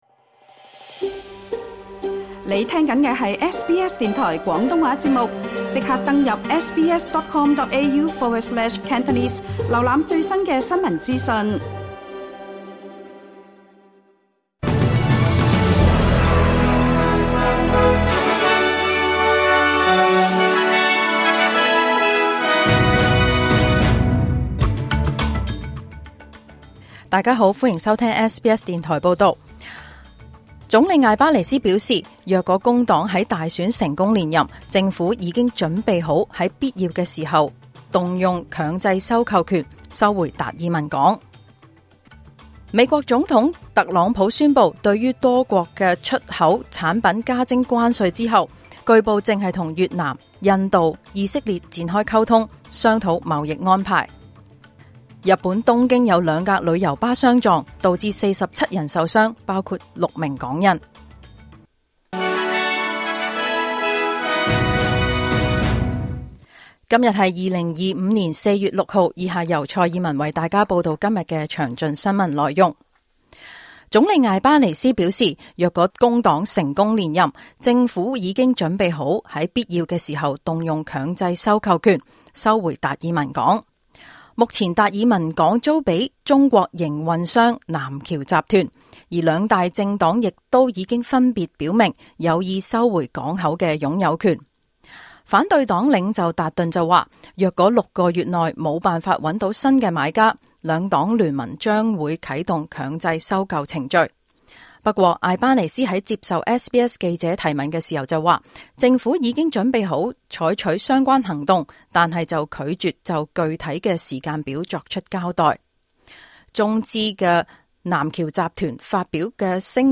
2025 年 4 月 6 日 SBS 廣東話節目詳盡早晨新聞報道。